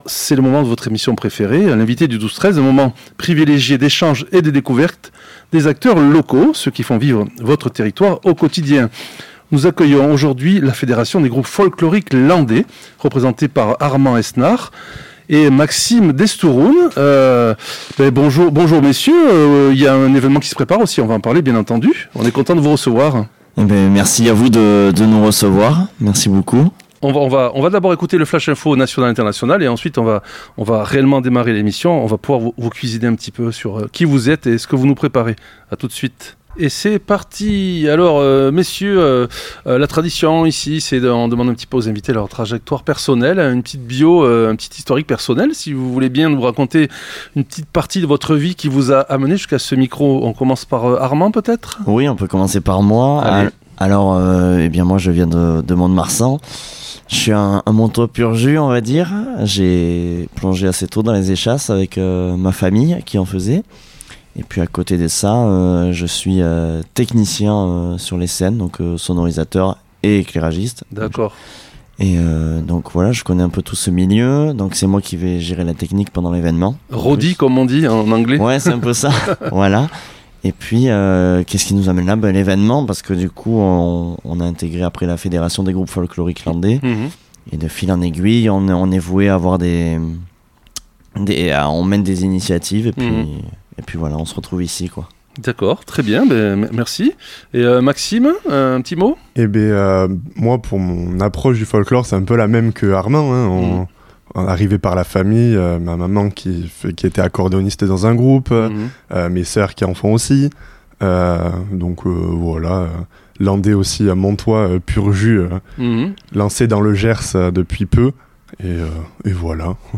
Nos deux invités, passionnés par la culture Landaise et Gasconne, sont venus nous présenter leur fédération qui rassemble jusqu'à maintenant 13 groupes folkloriques.